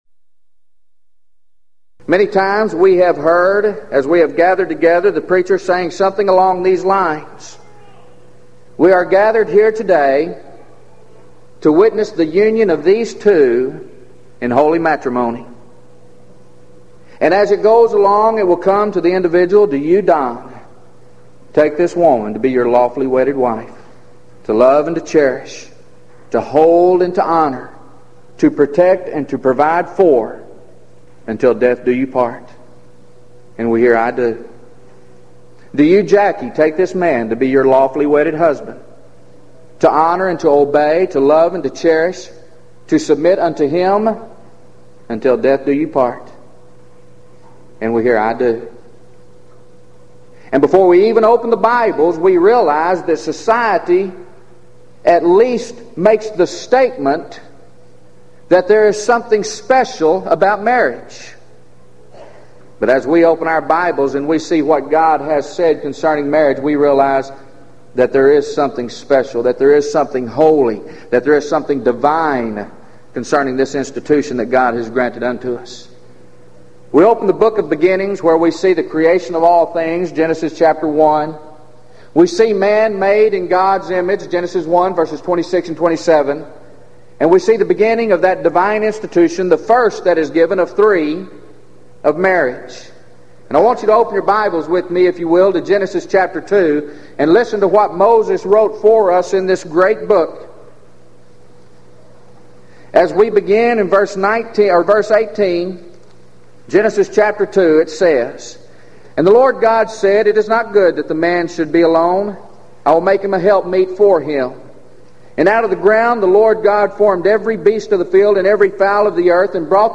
Event: 1997 Gulf Coast Lectures
lecture